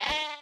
animalia_sheep_hurt.ogg